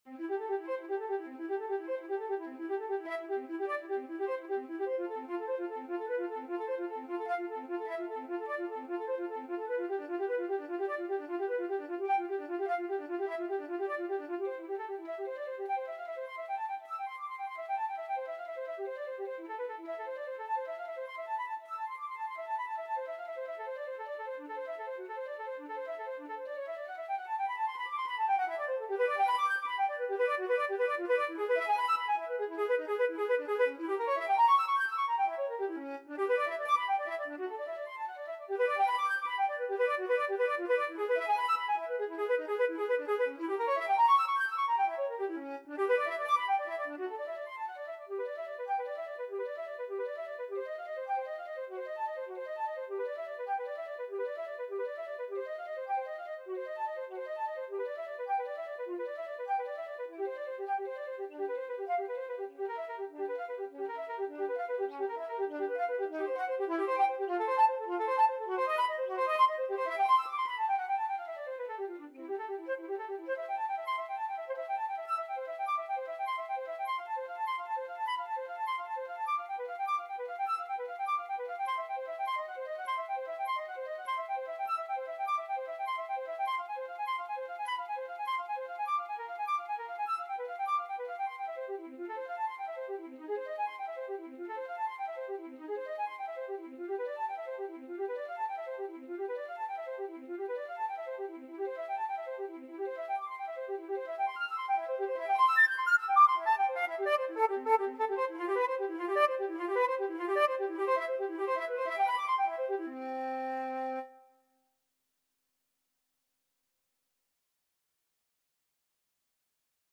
4/4 (View more 4/4 Music)
Vivace (View more music marked Vivace)
Flute  (View more Advanced Flute Music)
Classical (View more Classical Flute Music)